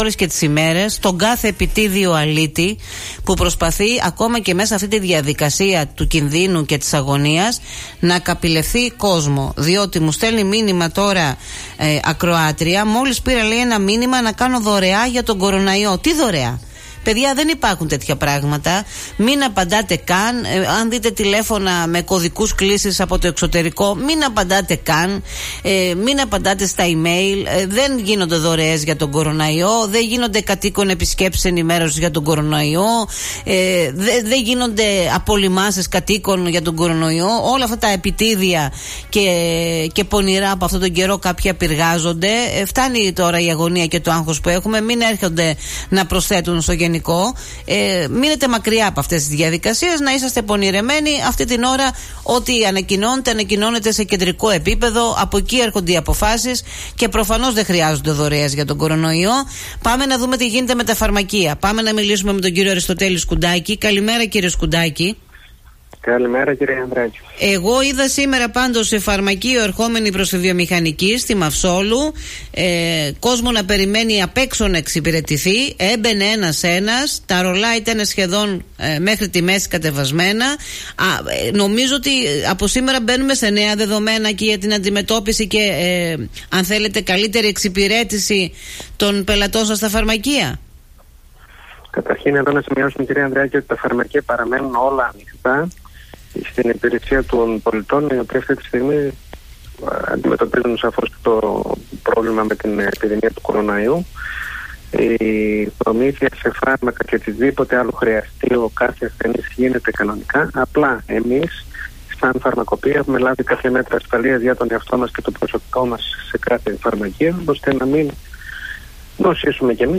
μίλησε στον Politica 89.8